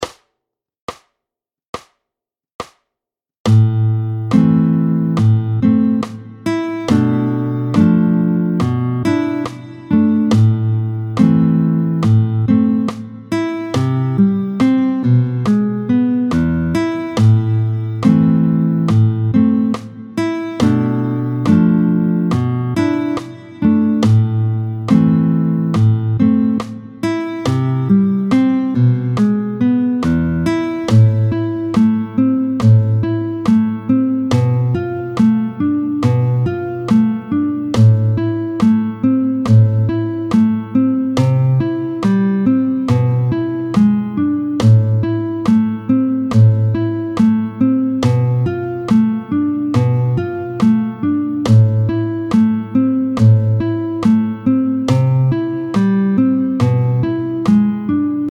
20-02 Y’a d’la nostalgie dans l’air, tempo 70